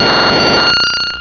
sovereignx/sound/direct_sound_samples/cries/vileplume.aif at 2f4dc1996ca5afdc9a8581b47a81b8aed510c3a8
vileplume.aif